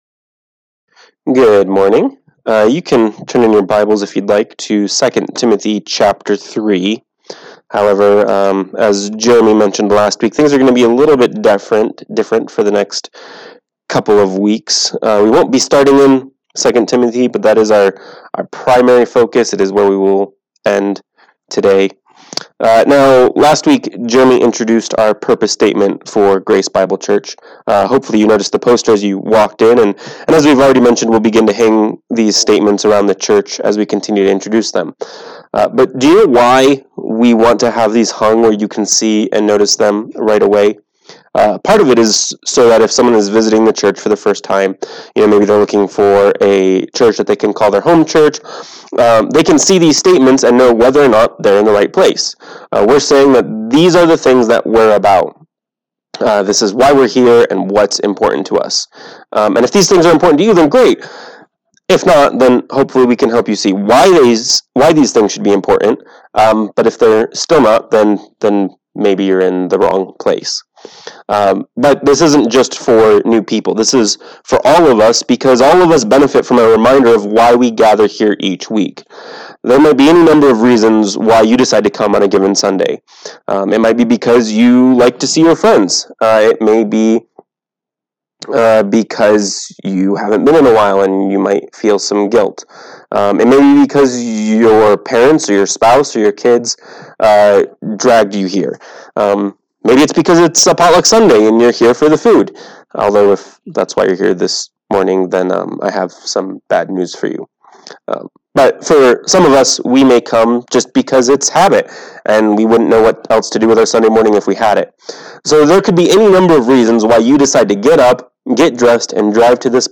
Note: The Livestream Audio cuts out at about the 22 minute mark. The MP3 Audio is complete.
Service Type: Sunday Morning Worship